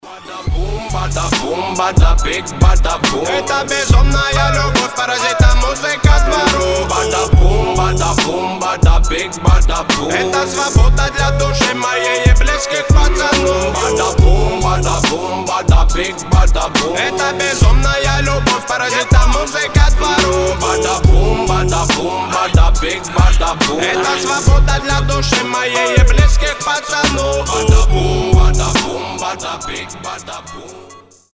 Рэп рингтоны
Хип-хоп